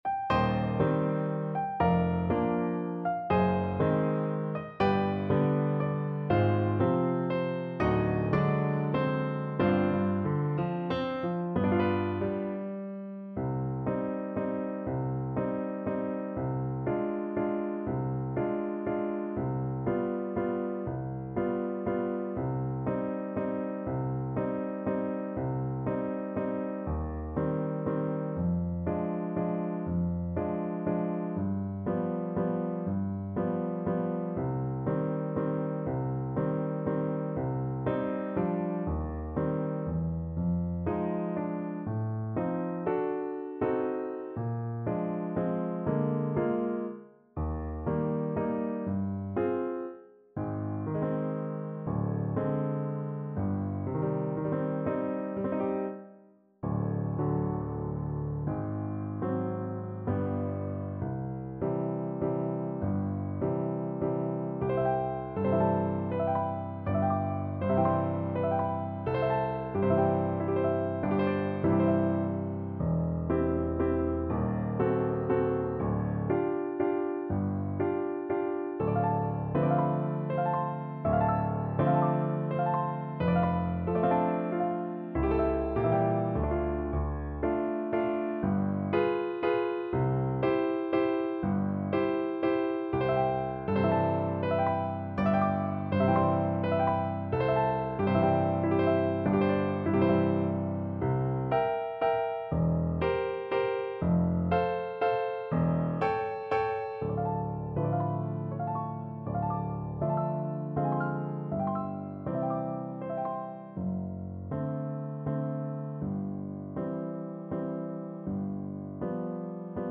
3/4 (View more 3/4 Music)
~ = 120 Lento